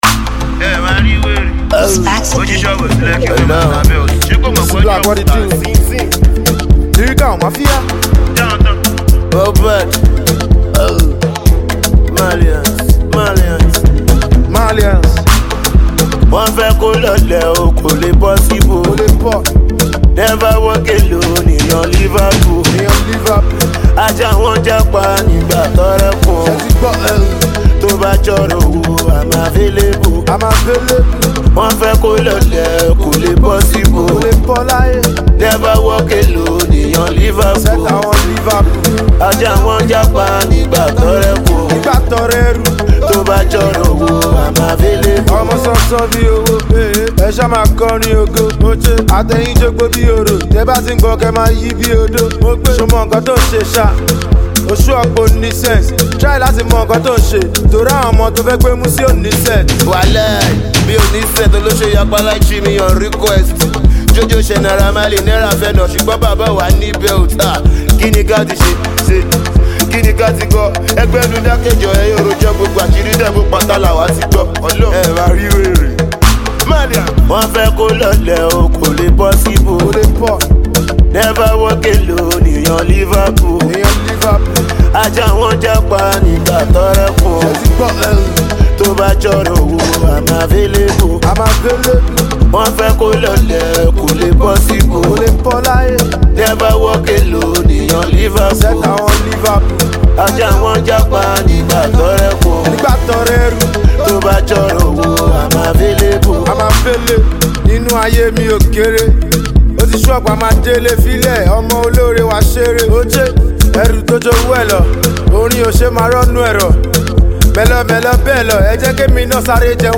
the street hop singer teams up with his label mate